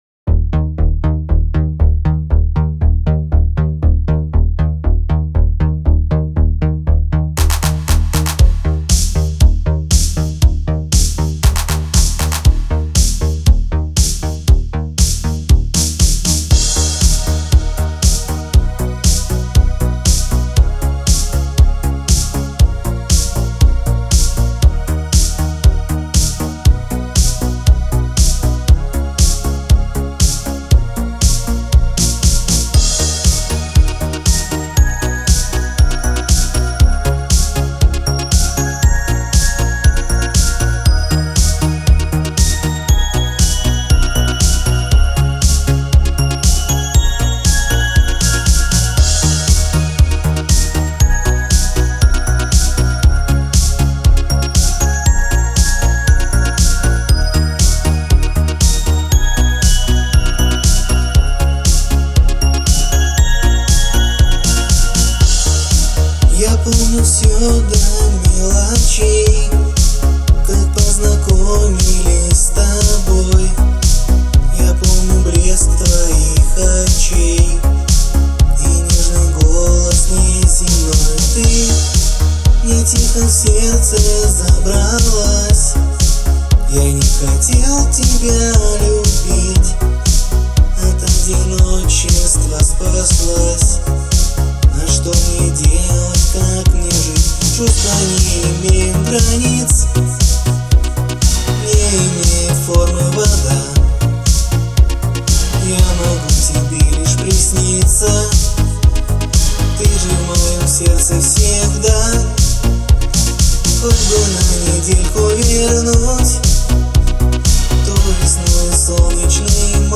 это эмоциональная поп-баллада